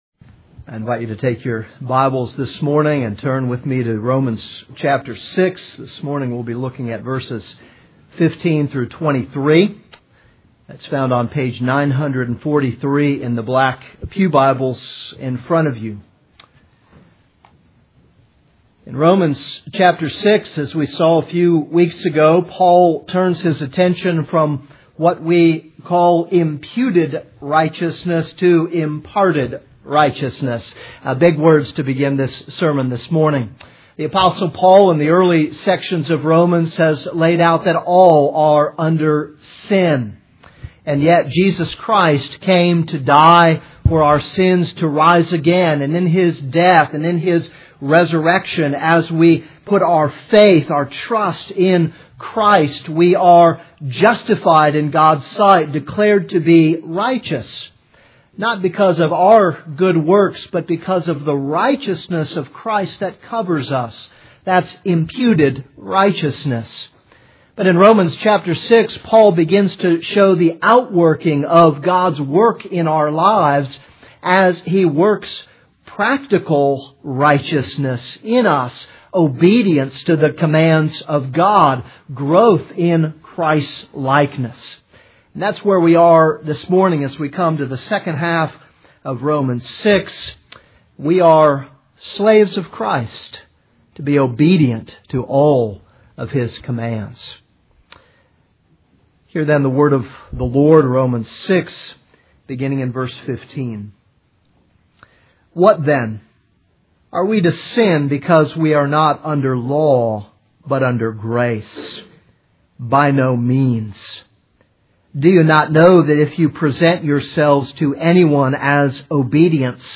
This is a sermon on Romans 6:15-23.